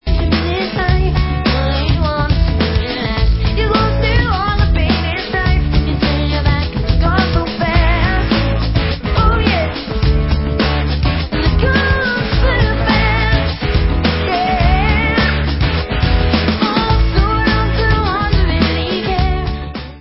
A COLLECTION OF FEEL-GOOD ANTHEMS OF SUMMER